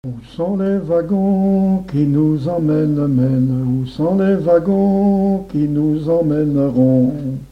Mémoires et Patrimoines vivants - RaddO est une base de données d'archives iconographiques et sonores.
marche de conscrits
Chants brefs - Conscription
Pièce musicale inédite